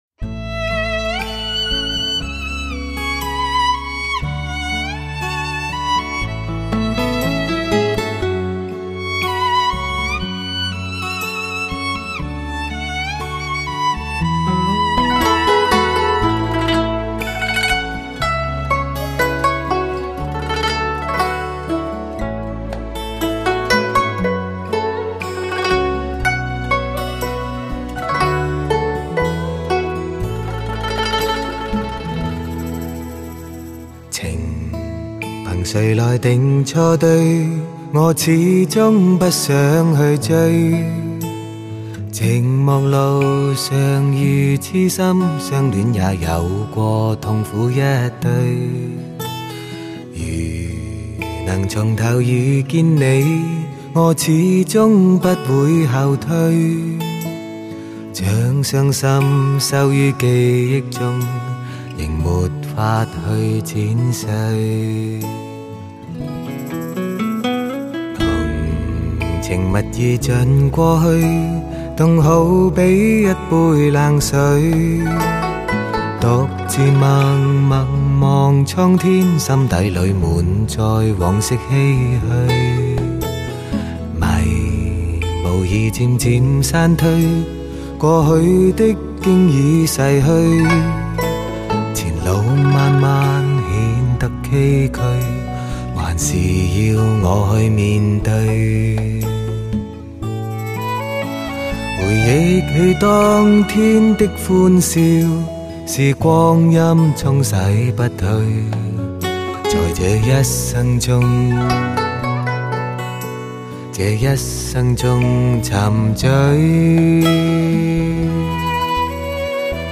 顶级发烧名声倾情对唱